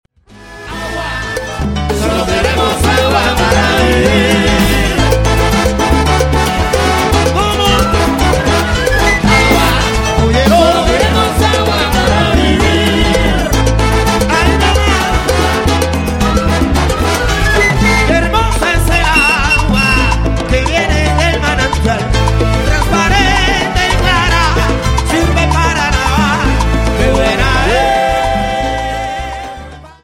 Salsa Charts - November 2008